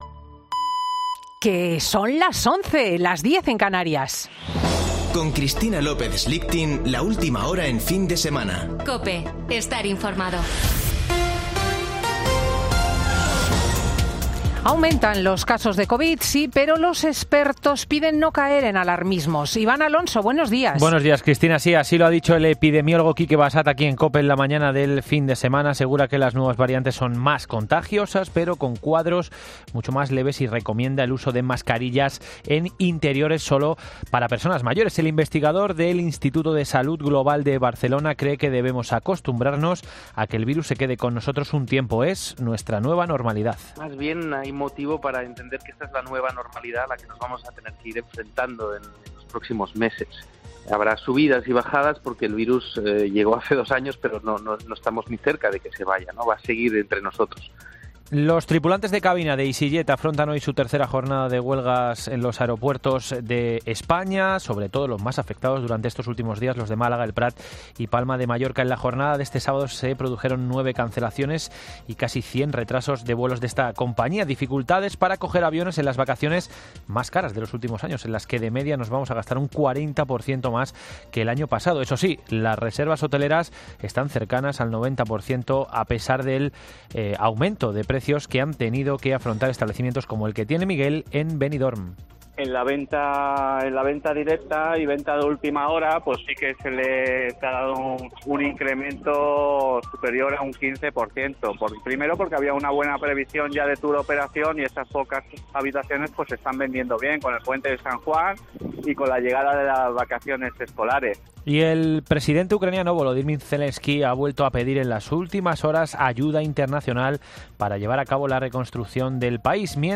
Boletín de noticias de COPE del 3 de julio de 2022 a las 11.00 horas